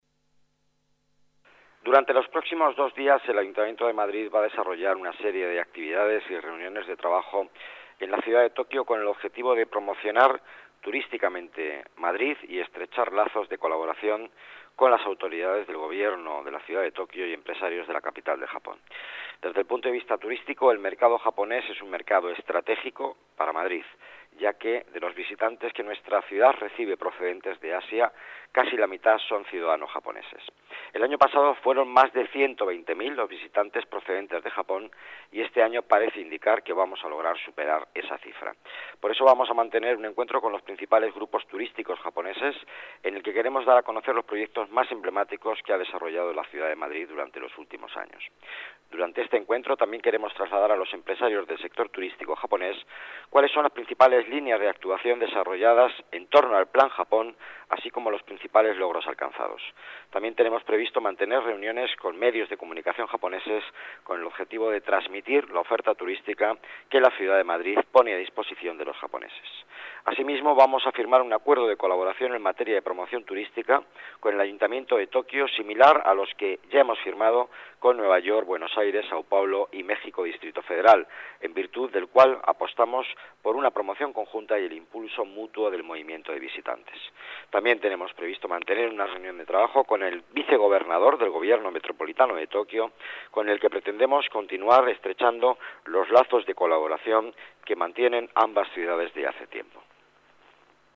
Nueva ventana:Palabras del delegado de Economía, Empleo y Participación Ciudadana, Miguel Ángel Villanueva